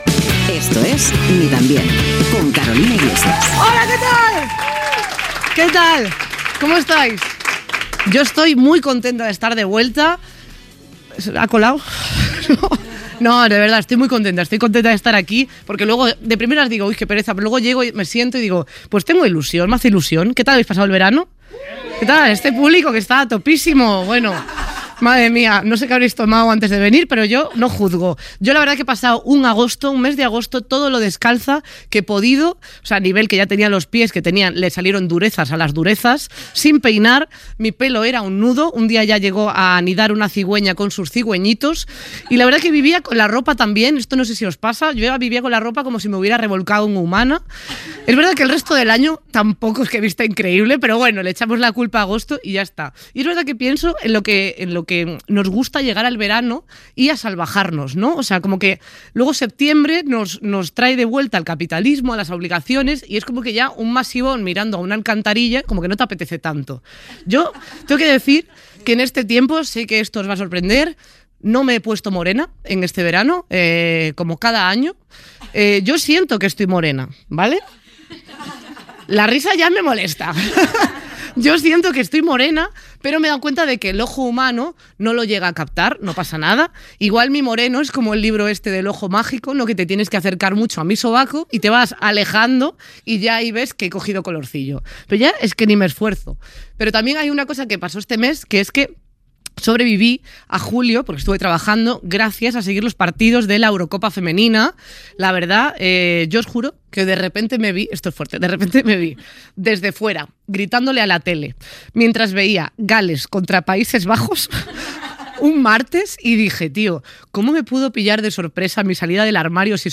Fragment d'una entrevista al periodista José Luis Sastre.
Entreteniment